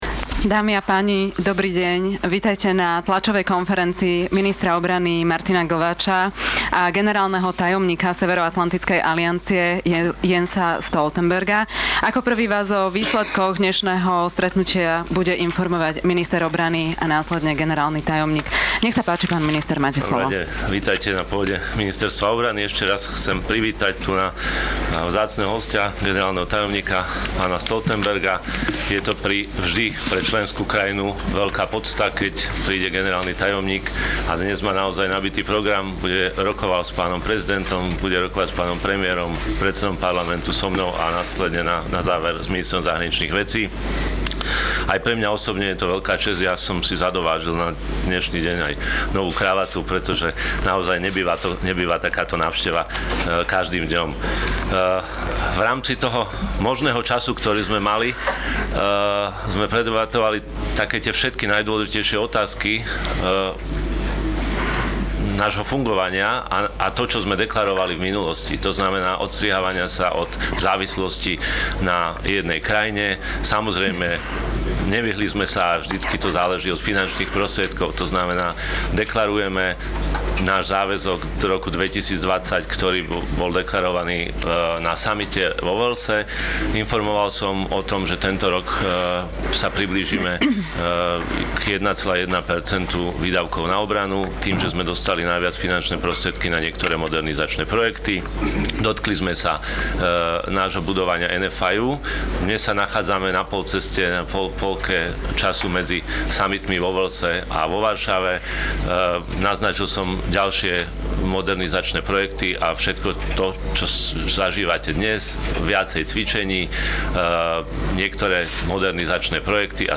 NATO Secretary General Joint Press Conference with Minister of Defence of the Slovak Republic